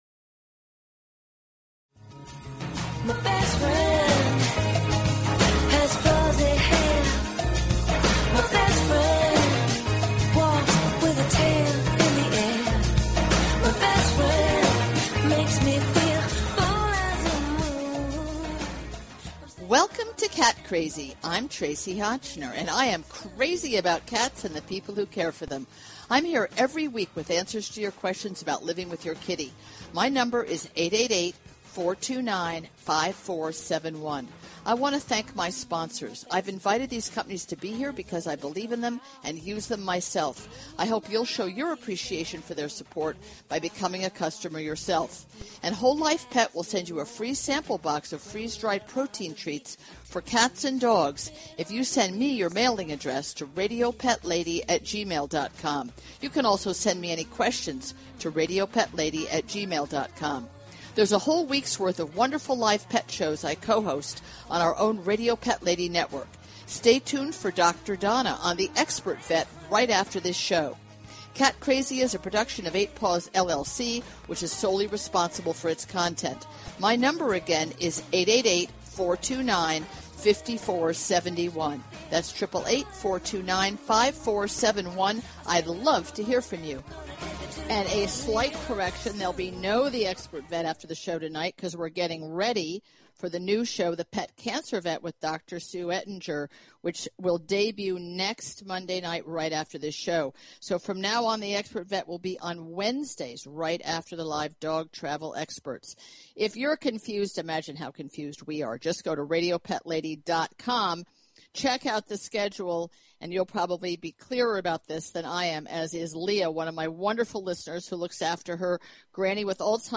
Talk Show Episode, Audio Podcast, Cat_Crazy and Courtesy of BBS Radio on , show guests , about , categorized as